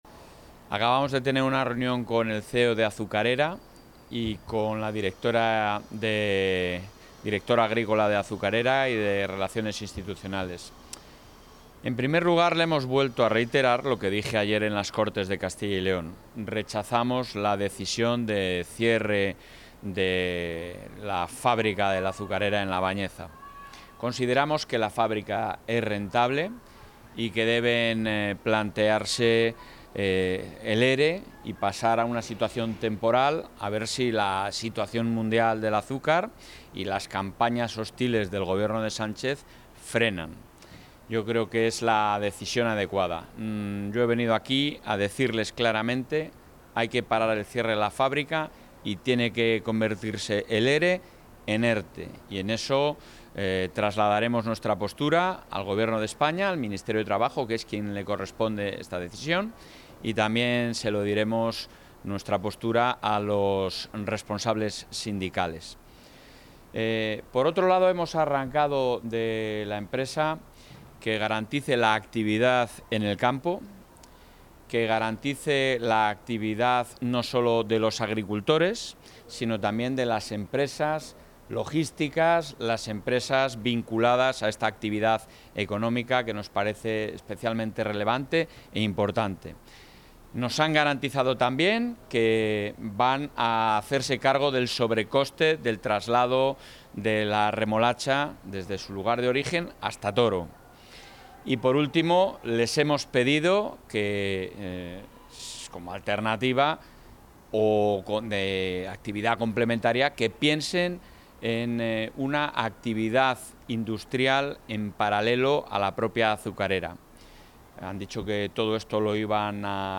Valoración del presidente de la Junta.